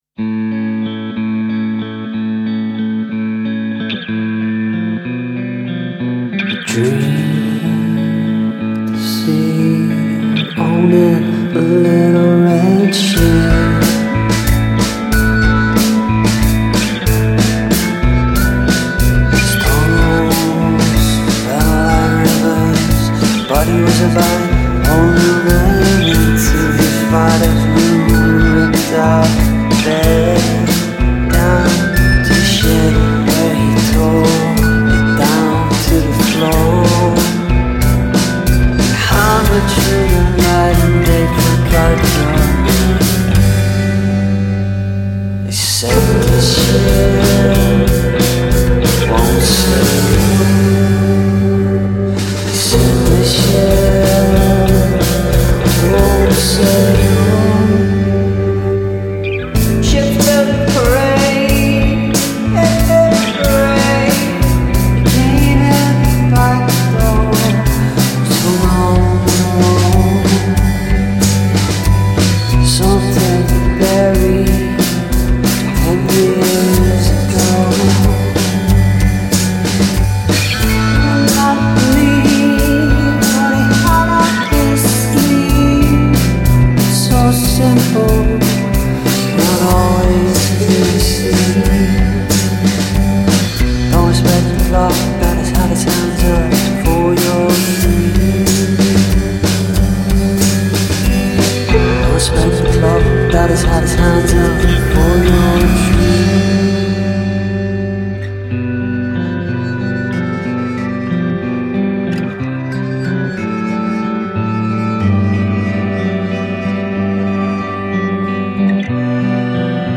Irish atmospheric indie-pop duo